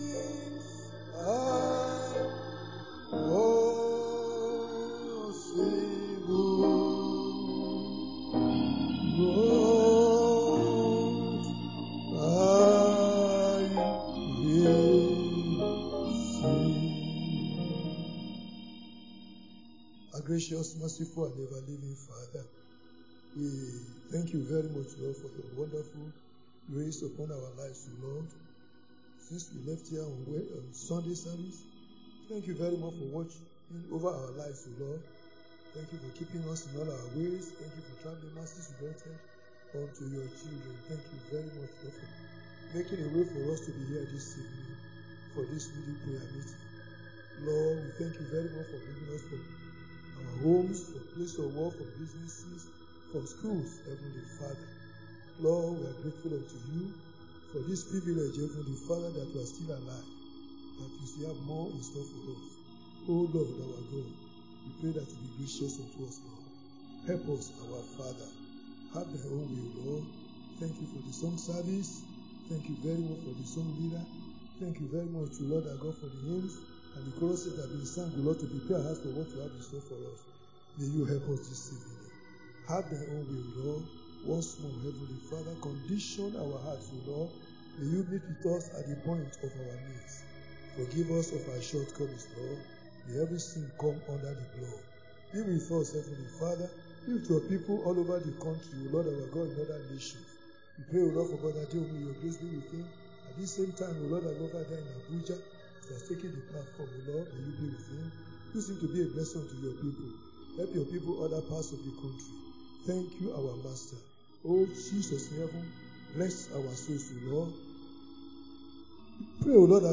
Wed. Prayer Meeting